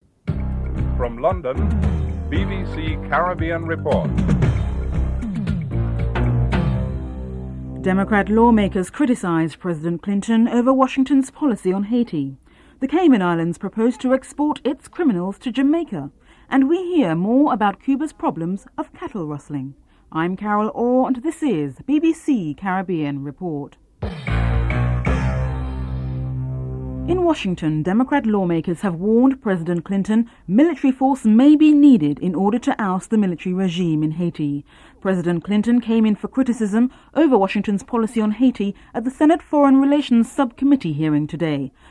Wrap up and theme music (14:55-15:05)